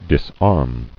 [dis·arm]